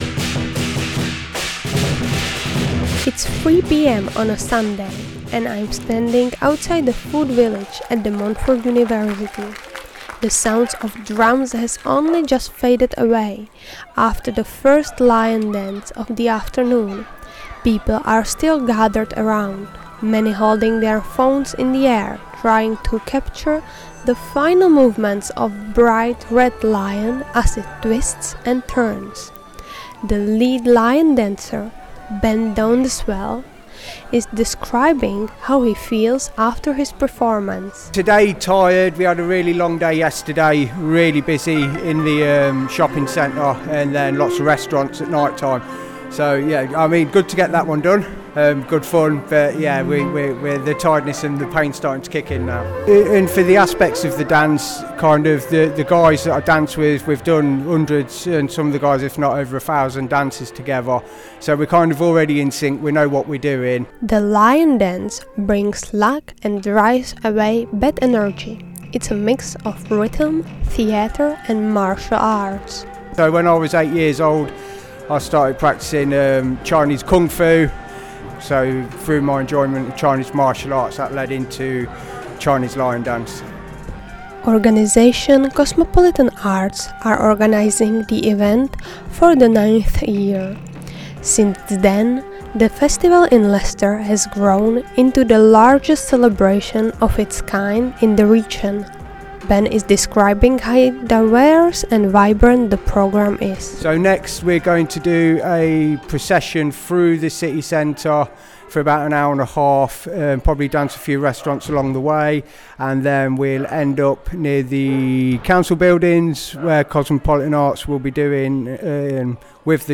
Dragons, drums, traditional costumes and hundreds gathering in the streets – what is going on?
The Chinese New Year celebrations are in full swing in Leicester.